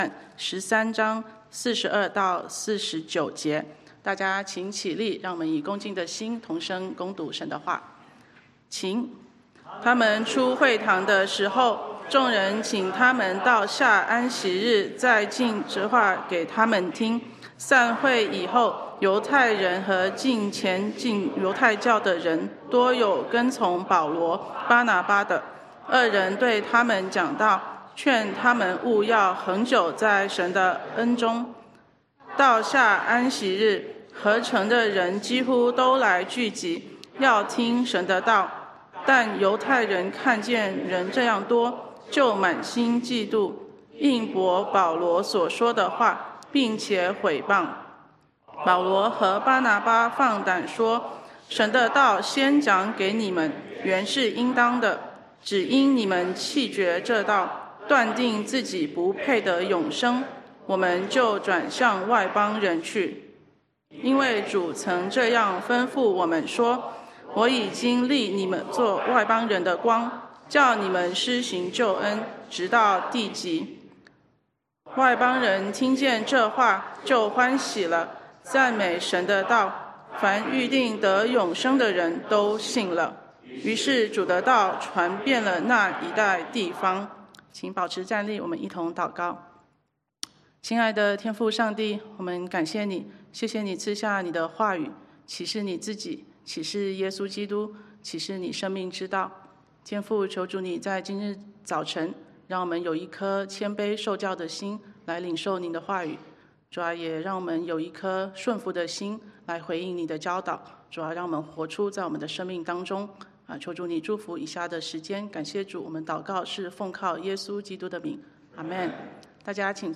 Service Type: 主日證道
中文早堂講道錄音